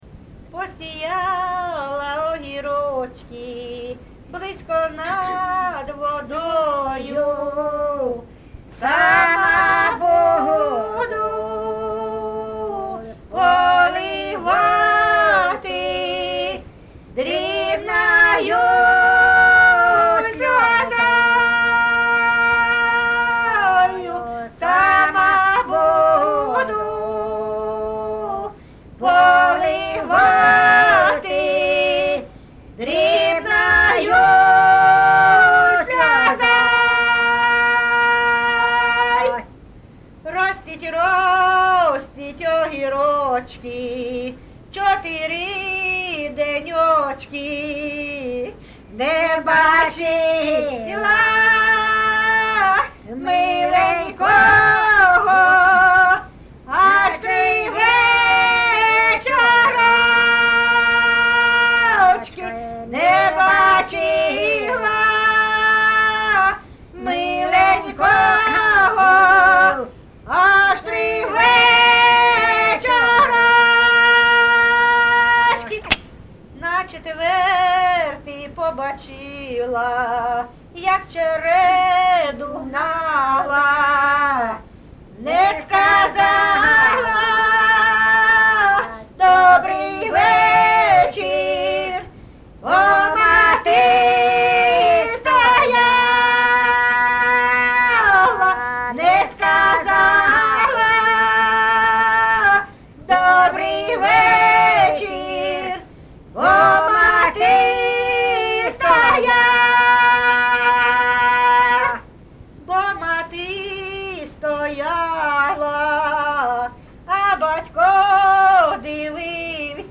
ЖанрПісні з особистого та родинного життя
Місце записус. Закітне, Краснолиманський (Краматорський) район, Донецька обл., Україна, Слобожанщина